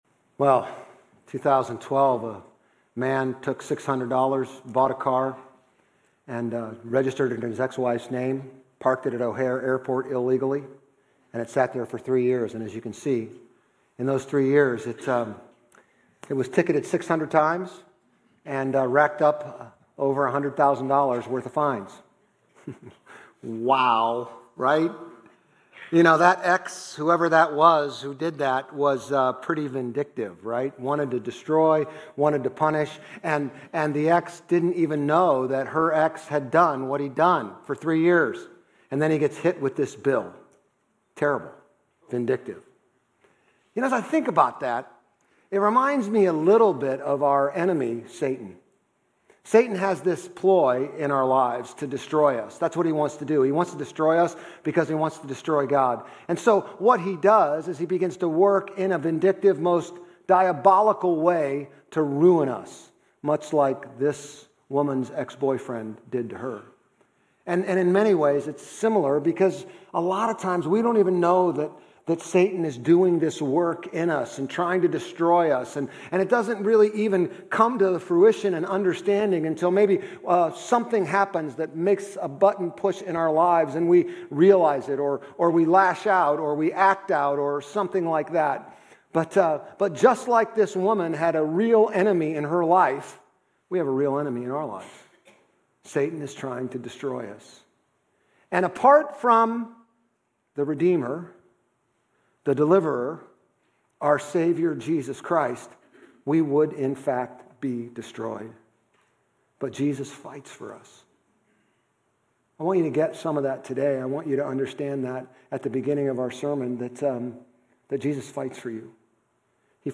Grace Community Church Old Jacksonville Campus Sermons 11_23 Old Jacksonville Campus Nov 24 2025 | 00:34:37 1x 00:00 / 00:34:37 Subscribe Share RSS Feed Share Link Embed